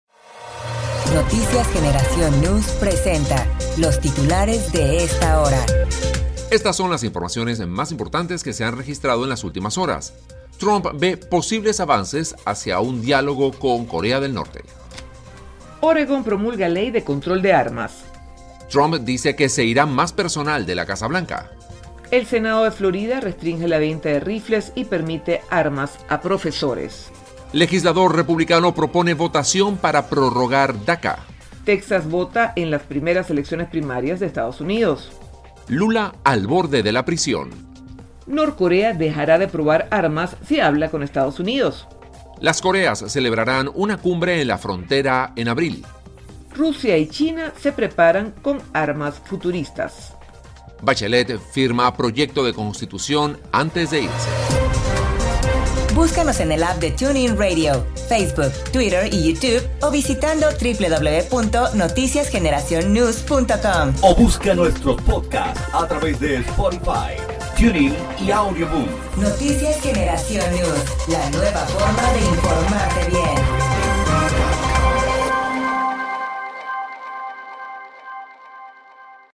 Titulares de Noticias